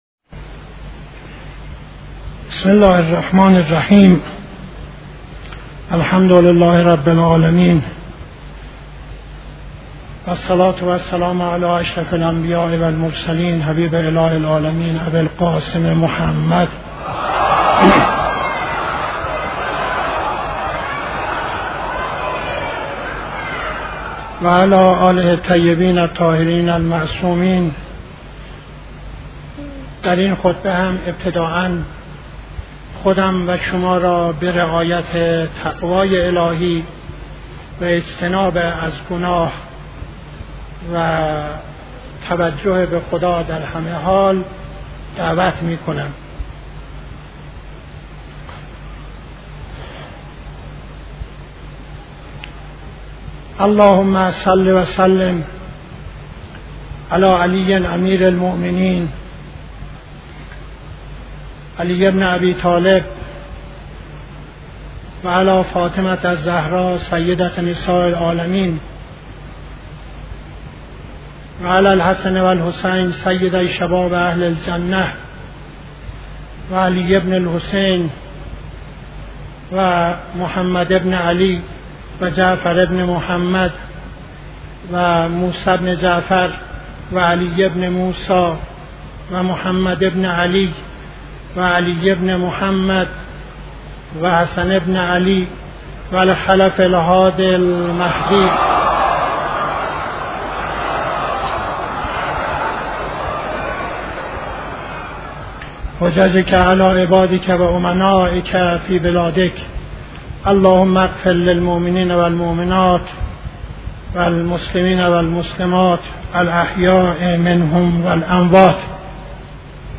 خطبه دوم نماز جمعه 03-05-71